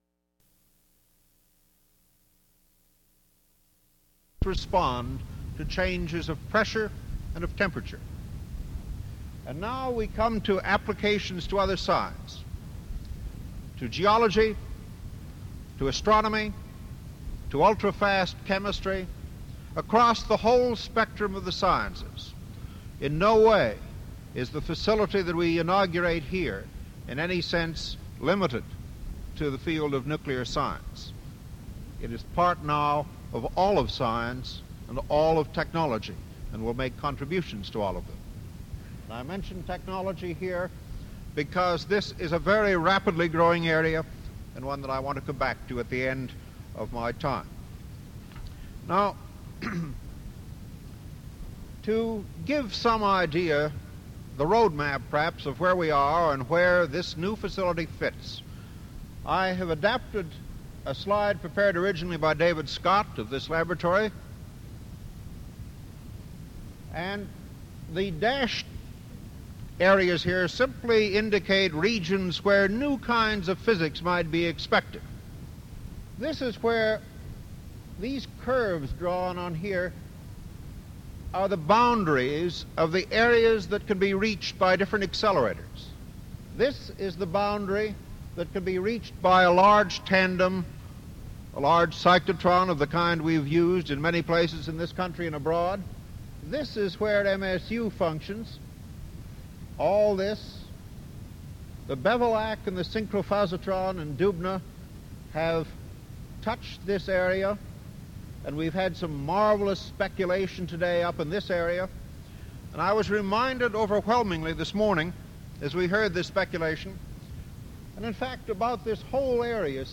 Interview
Audio/mp3 Original Format: Audiocassettes Resource Identifier